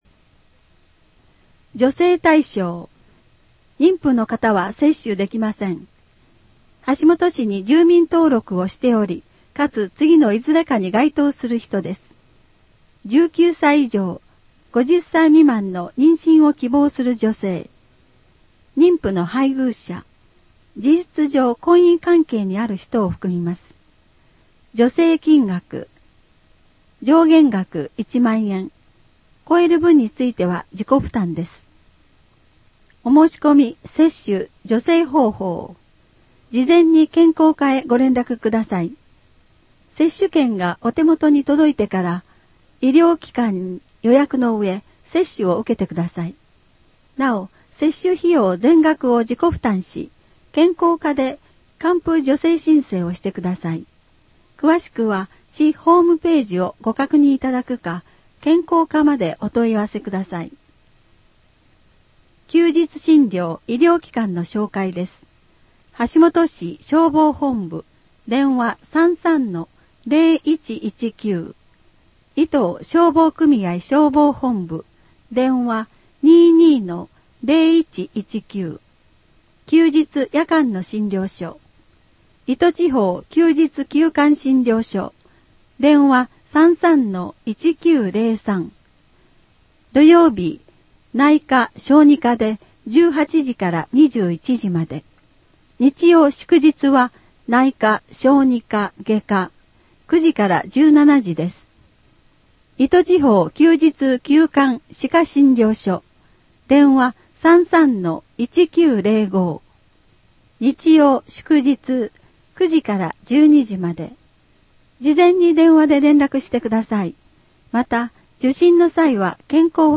WEB版　声の広報 2015年2月号